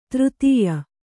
♪ třtīya